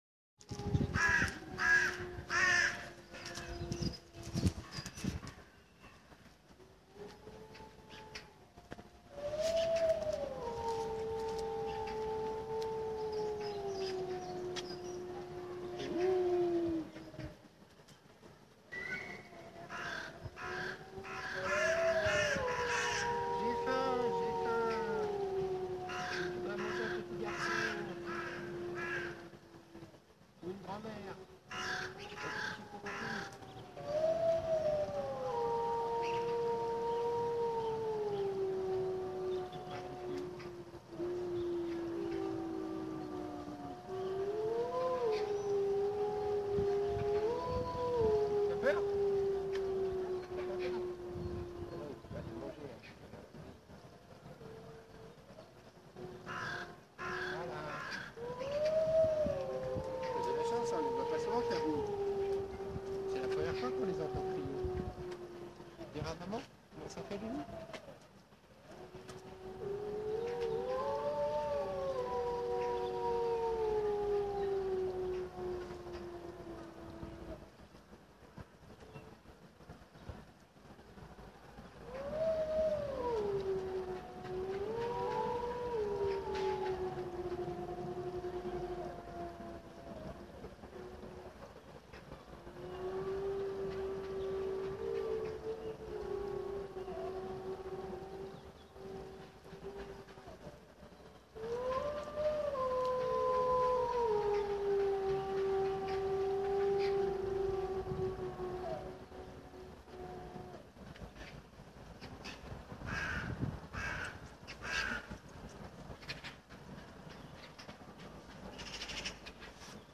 Concert de loups
Enregistré un dimanche de mars 2016 vers 10 heures, un concert émouvant pour quelques privilégiés...
loup.mp3